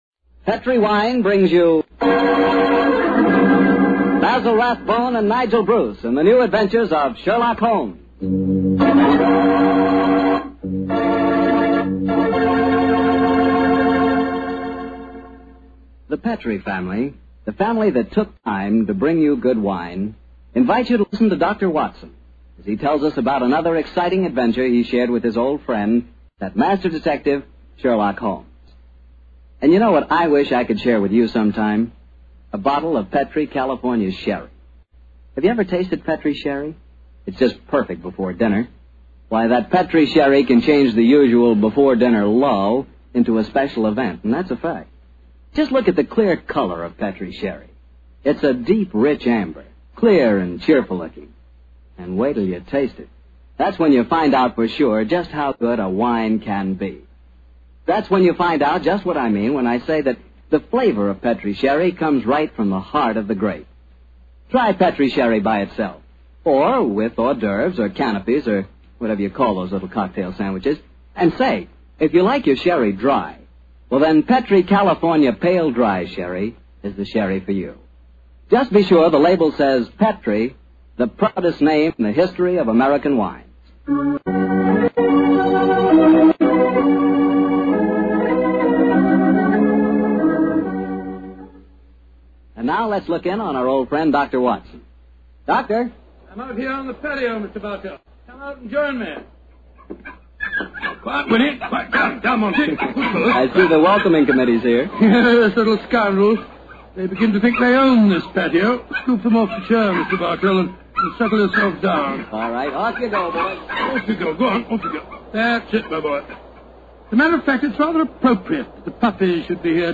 Radio Show Drama with Sherlock Holmes - Colonel Warburtons Madness 1945